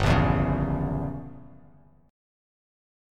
Gdim7 chord